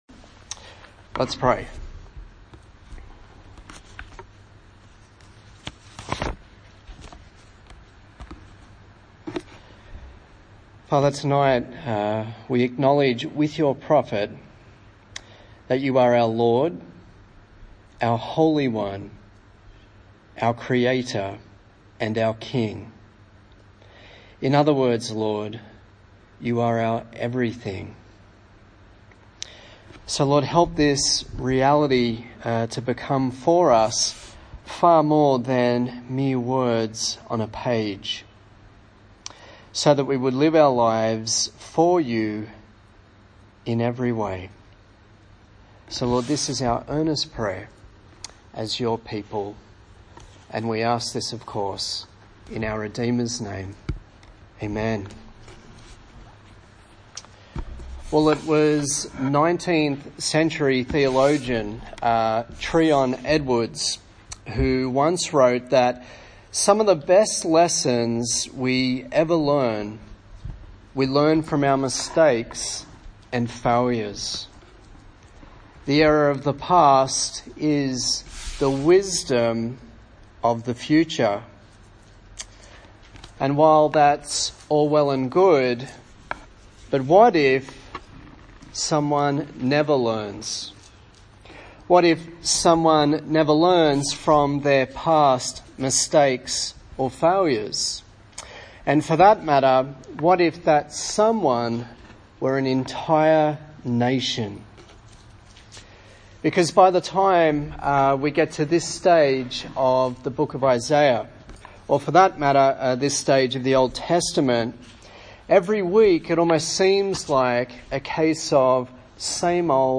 Passage: Isaiah 43:14-44:5 Service Type: TPC@5 A sermon in the series on the book of Isaiah